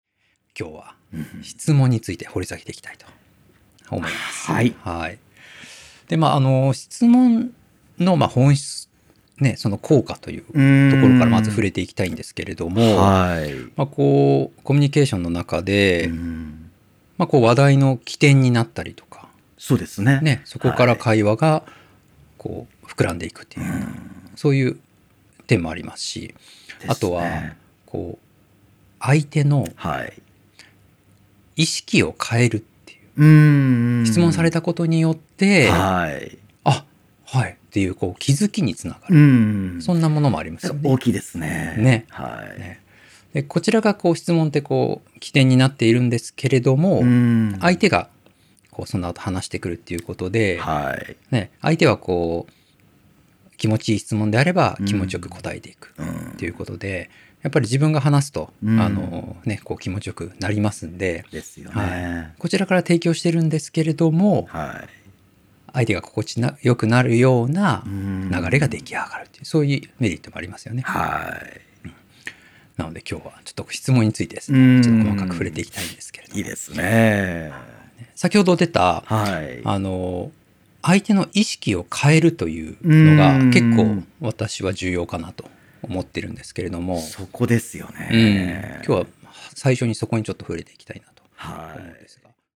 この音声講座は上記の「カートに入れる」ボタンから個別購入することで聴くことができます。